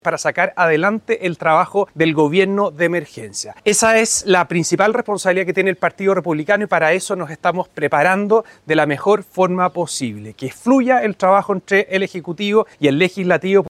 El presidente del Partido Republicano, Arturo Squella, dijo que el objetivo último es trabajar por las líneas del “gobierno de emergencia”.